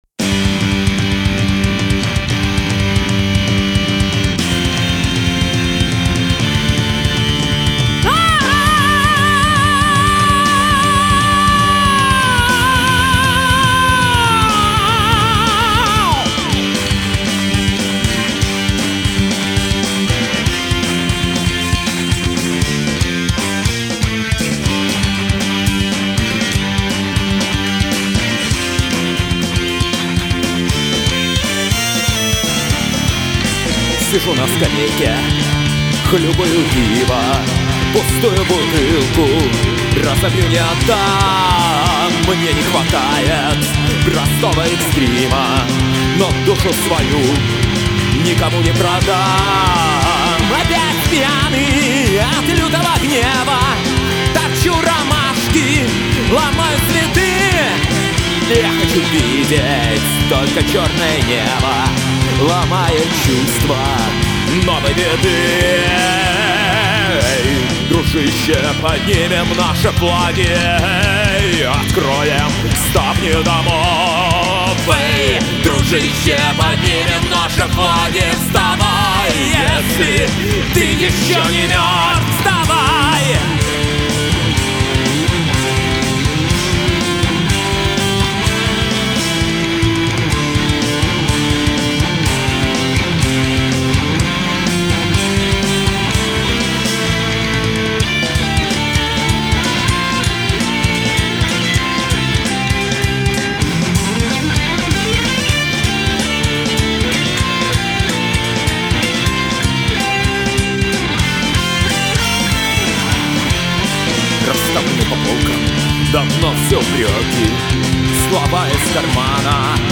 Metal heavy metal folk experimental 1.